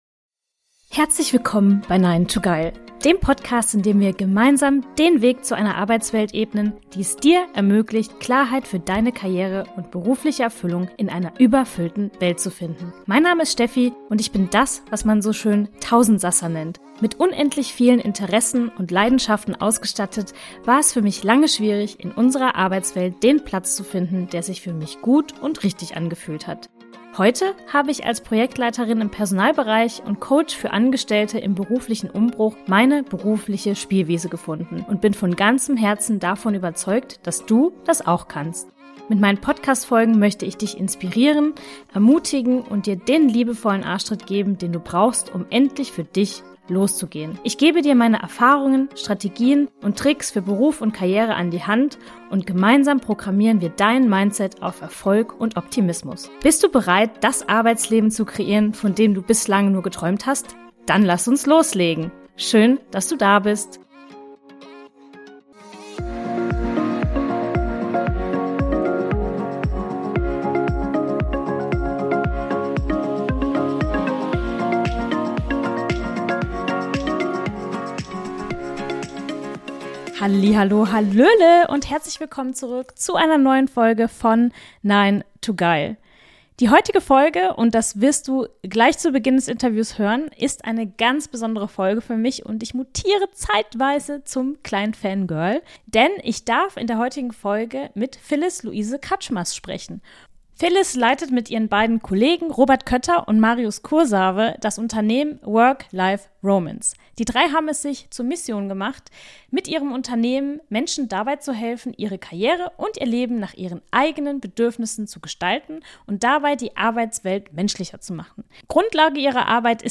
Wir sprechen in unserem Interview unter anderem über die folgenden Themen: